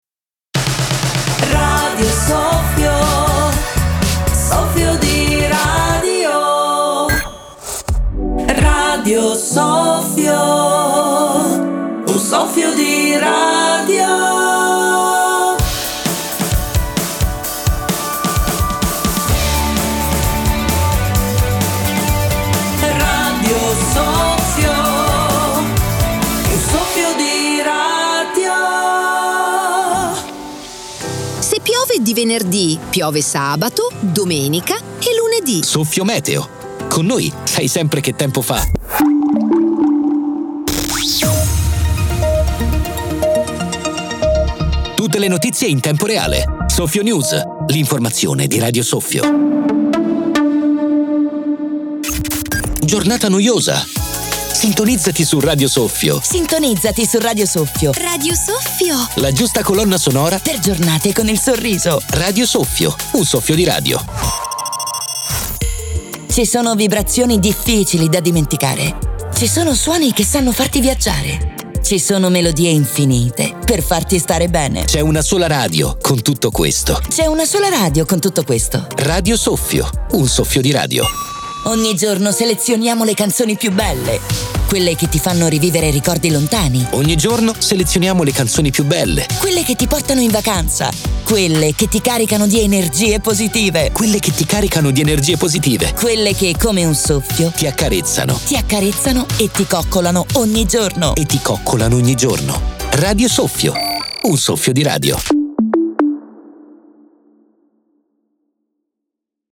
Dai jingles agli station, alle sigle.